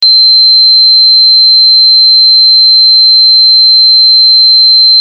A4 = 432 Hz